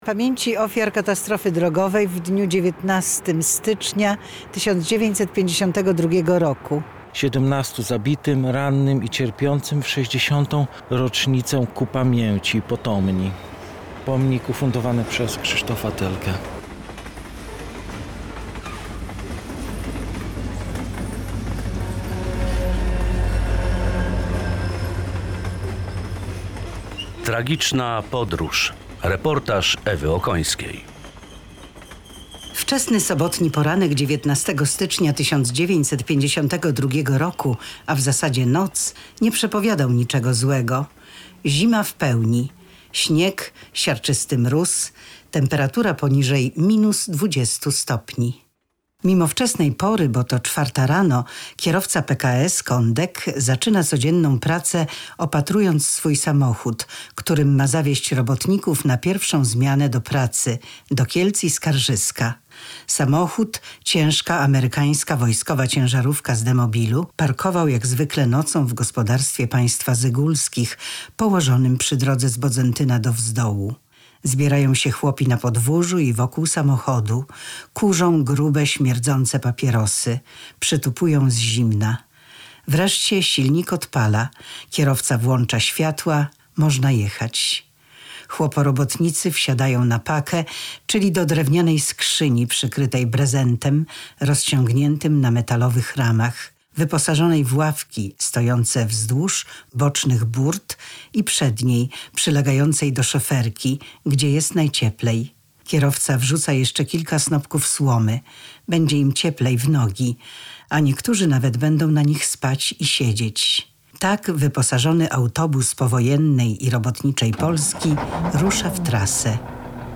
Tagi: reportaż